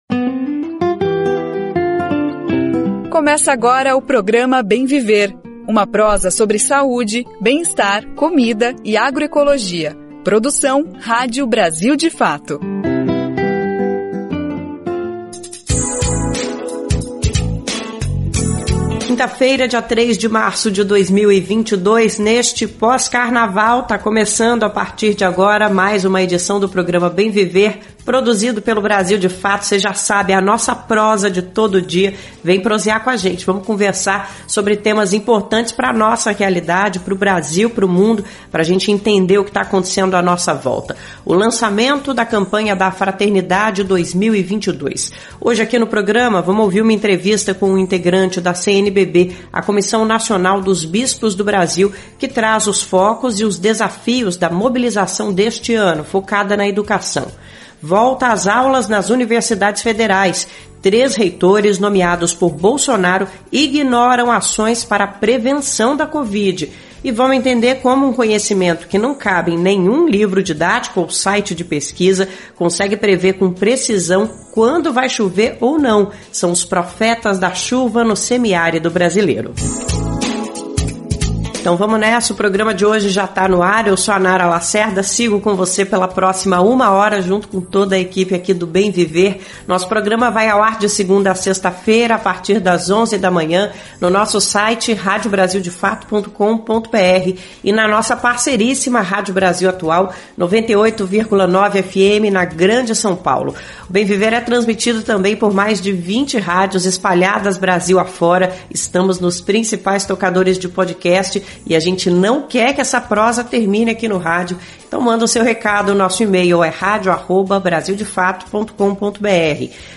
Para entender mais da campanha, a edição de hoje (3) do Programa Bem Viver repercute uma entrevista do programa Central do Brasil